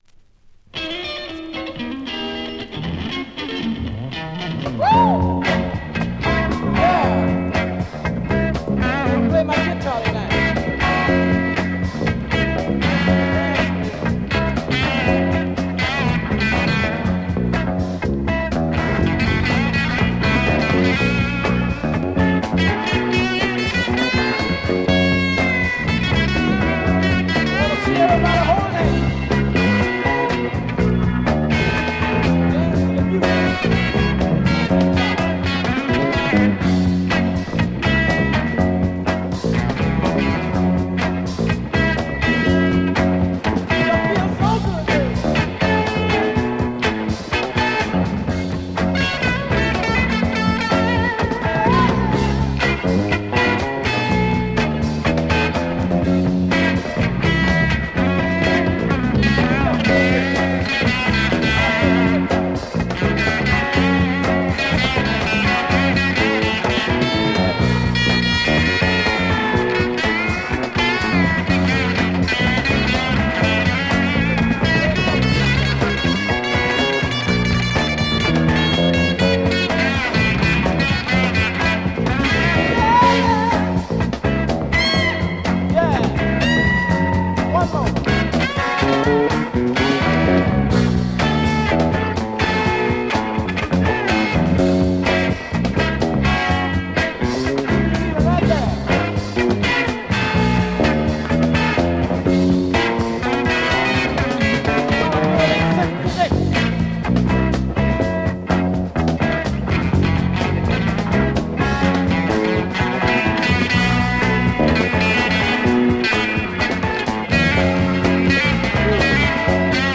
originally recorded in 66.